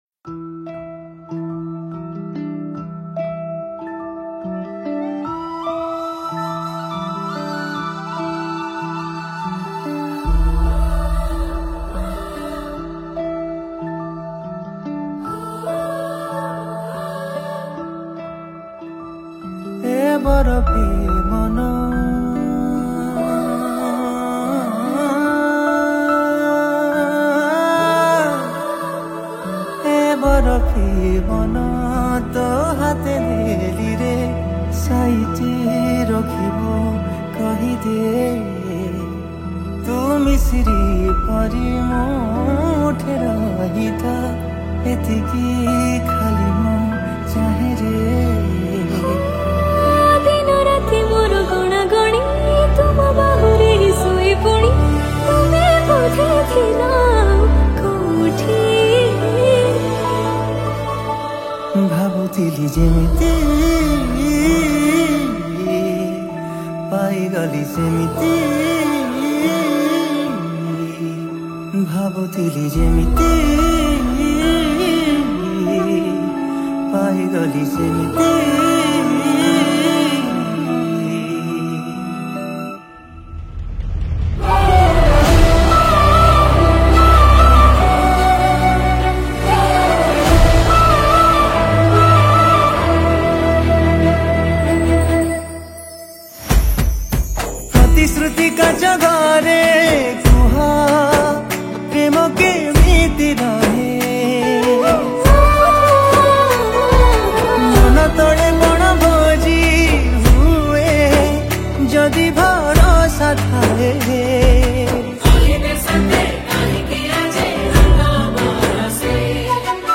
Chorus
Wind Instruments